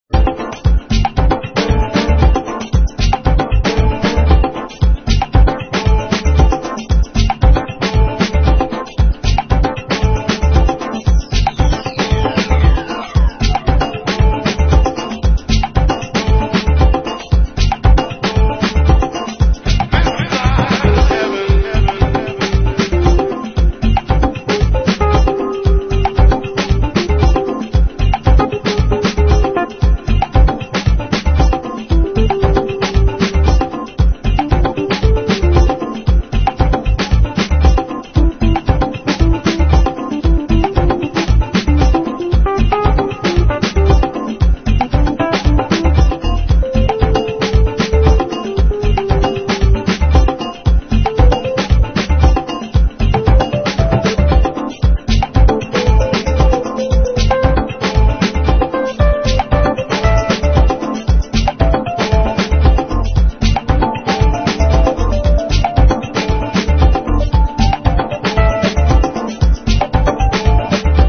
DEEP HOUSE / EARLY HOUSE# NU-DISCO / RE-EDIT